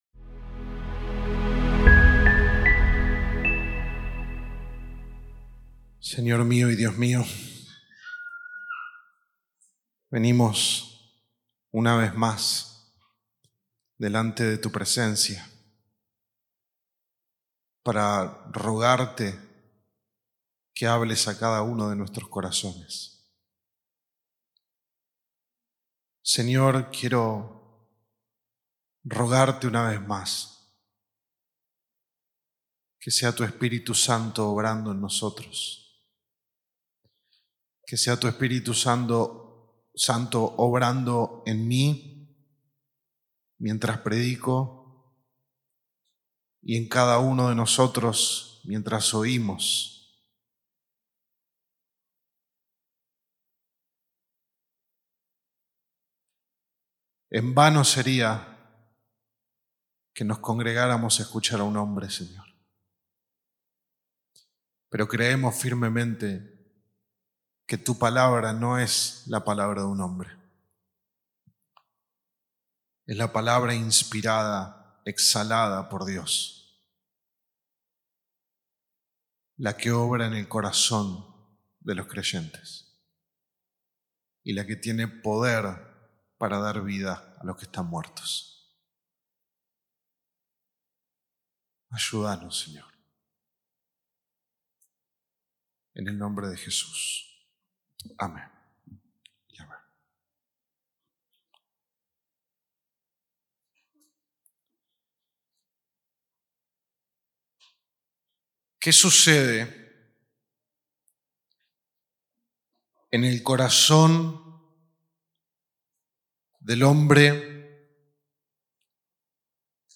Sermón 2 de 8 en Delante de Dios